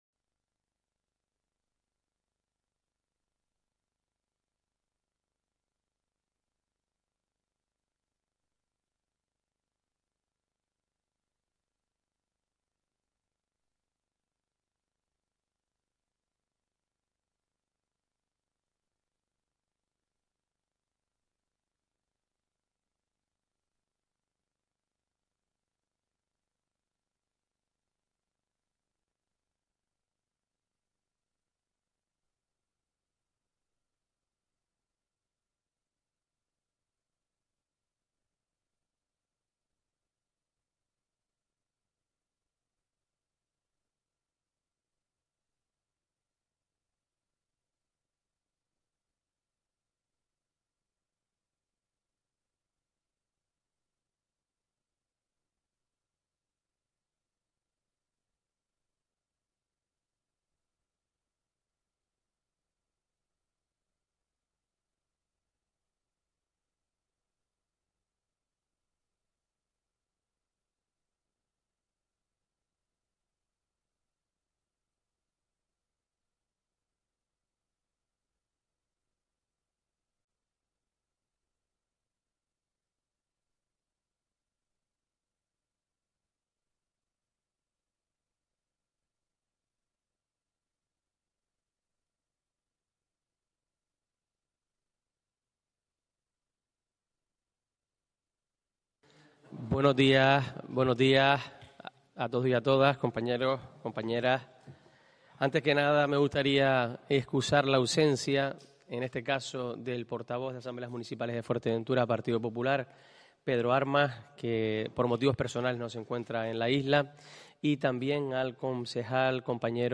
Pleno Extraordinario Pájara 24/04/24
Les traemos el pleno extraordinario de Pájara celebrado el día 22 de abril de 2024 Ya puedes escuchar el audio o descargarlo.